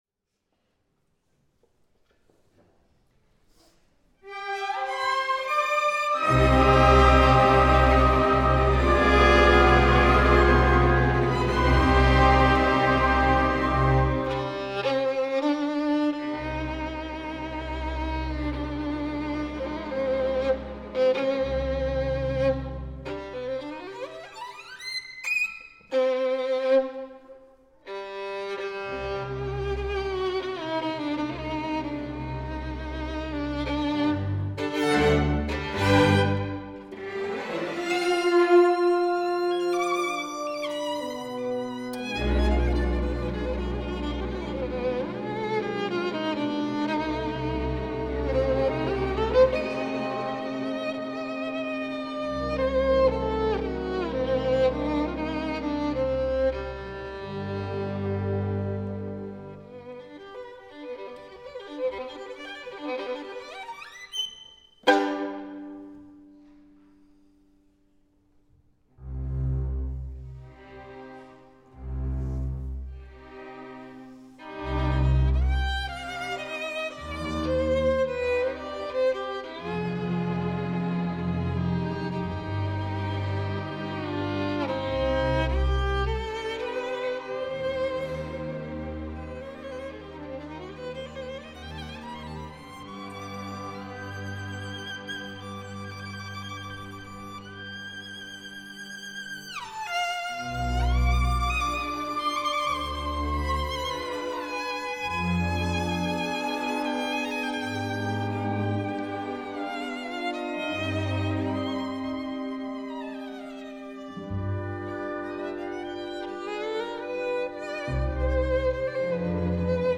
Zigunerweisen with Orchestra